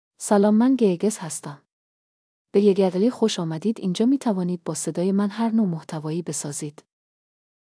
Dilara — Female Persian AI voice
Dilara is a female AI voice for Persian (Iran).
Voice sample
Listen to Dilara's female Persian voice.
Dilara delivers clear pronunciation with authentic Iran Persian intonation, making your content sound professionally produced.